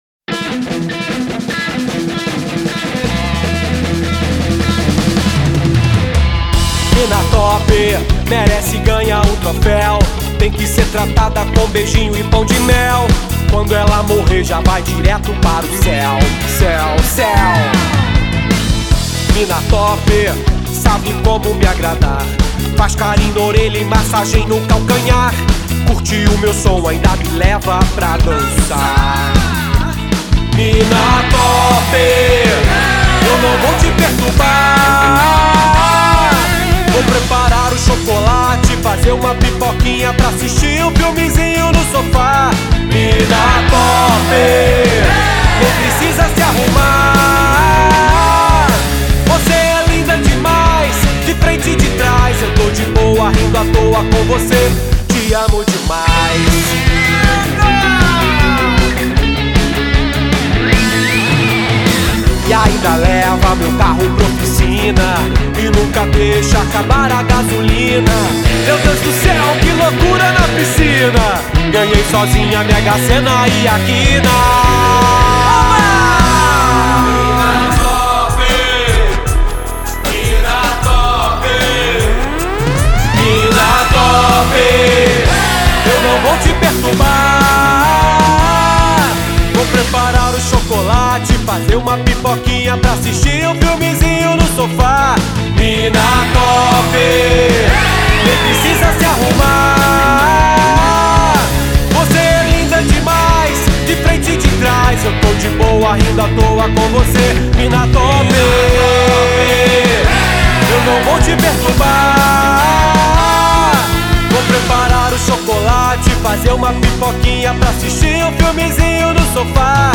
EstiloParódia / Comédia